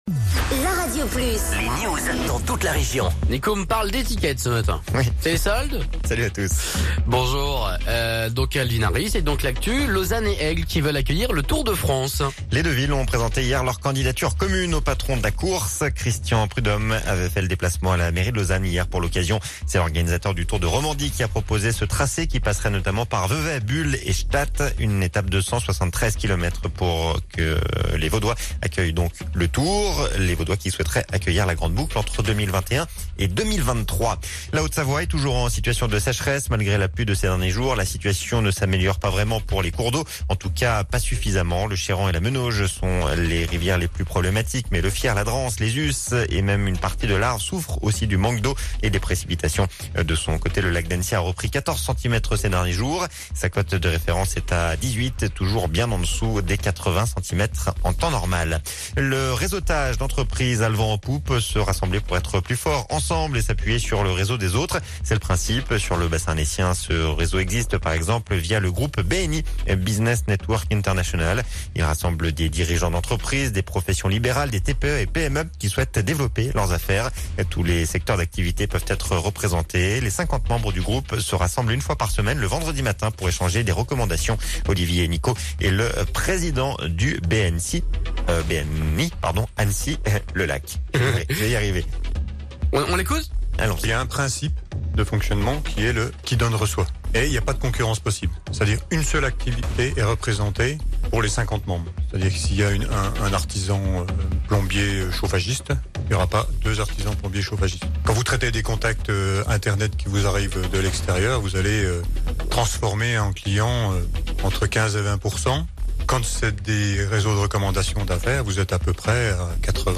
Reportage sur le lancement de Glisse en coeur au Grand-Bornand